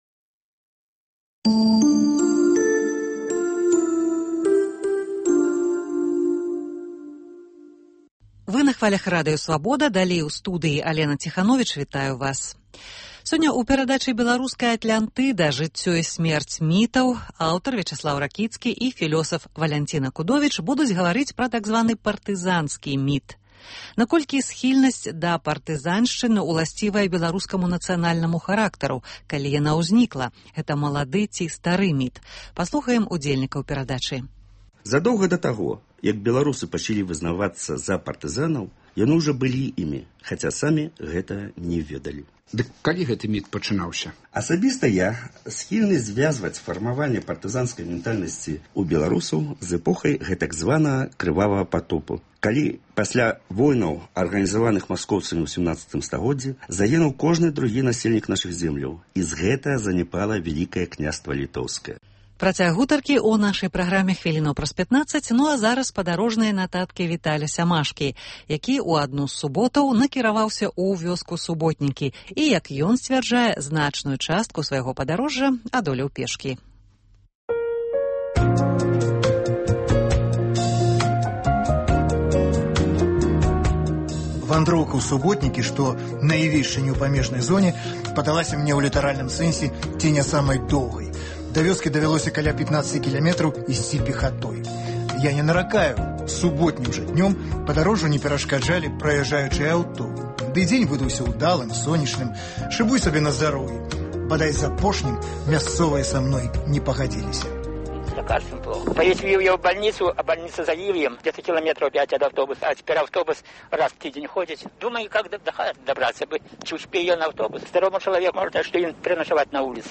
Паездкі нашых карэспандэнтаў па гарадах і вёсках Беларусі